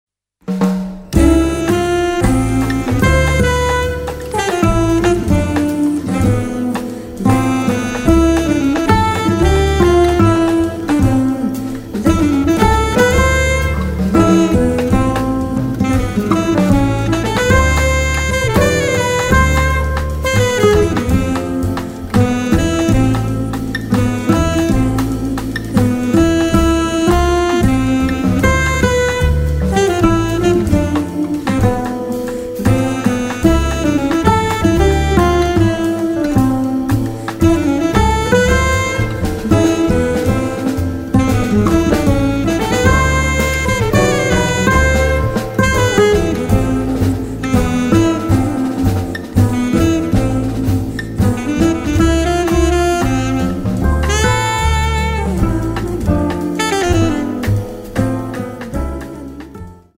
guitar
sax
piano
bass
drums
voice